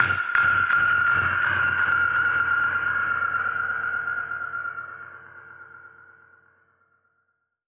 Index of /musicradar/dub-percussion-samples/125bpm
DPFX_PercHit_E_125-03.wav